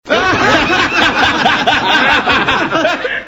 fx-risas.mp3